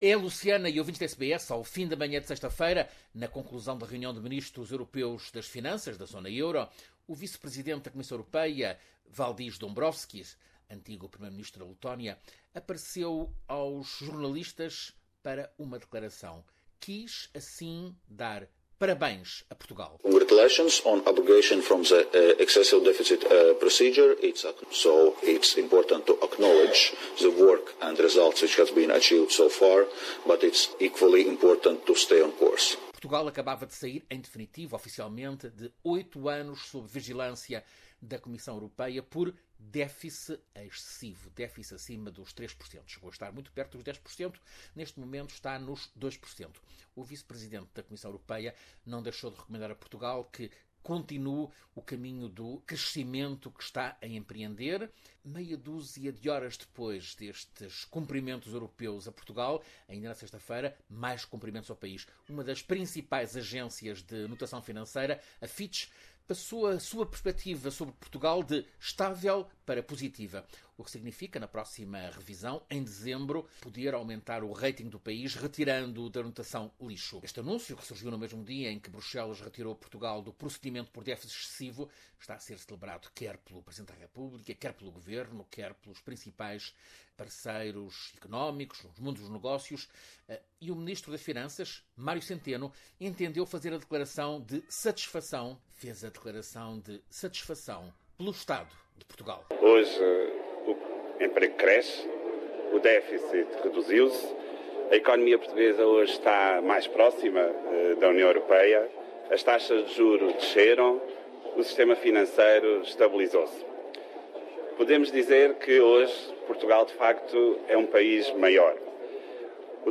A agência de notação financeira Fitch reviu a perspectiva da dívida pública portuguesa de estável para positiva. Ouça reportagem